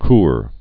(kr, kôr)